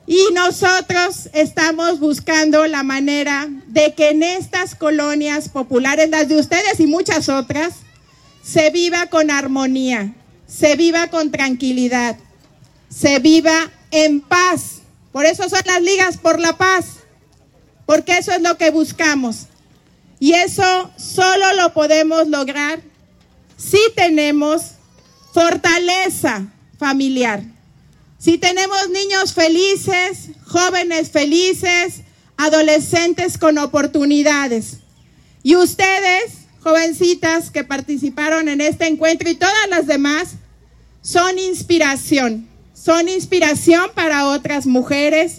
AudioBoletines
Lorena Alfaro García, presidenta municipal de Irapuato